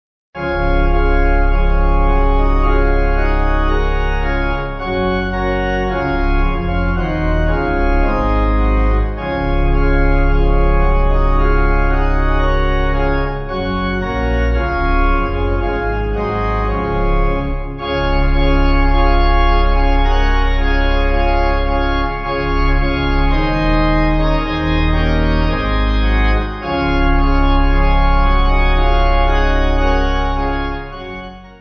Organ
(CM)   4/Db